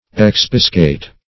Expiscate \Ex*pis"cate\, v. t. [L. expiscatus, p. p. of